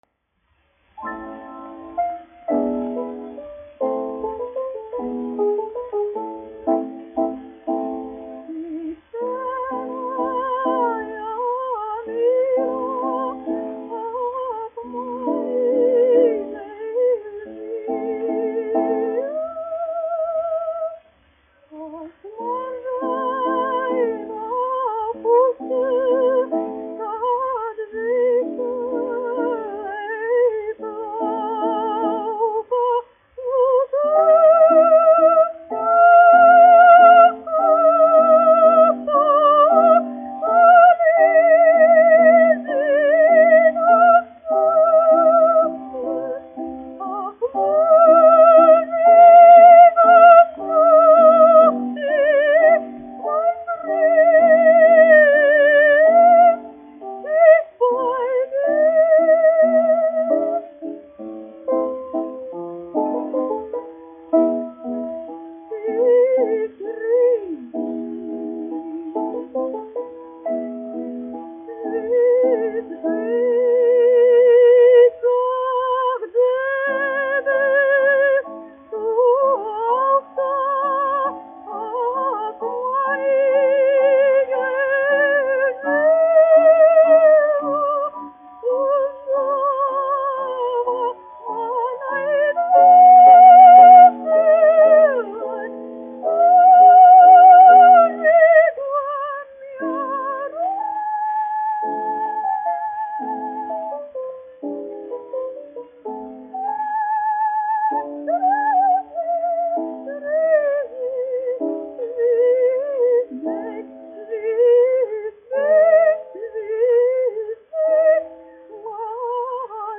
Benefelde, Ada, 1887-1967, dziedātājs
1 skpl. : analogs, 78 apgr/min, mono ; 25 cm
Operas--Fragmenti, aranžēti
Skaņuplate
Latvijas vēsturiskie šellaka skaņuplašu ieraksti (Kolekcija)